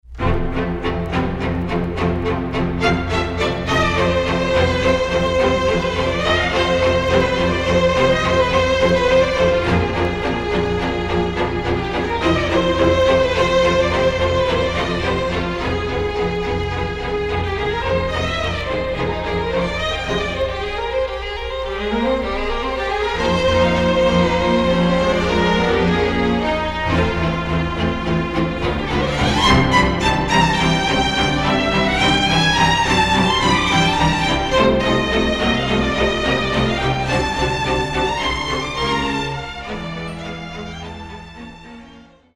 BARTOK Divertimento Allegro non troppo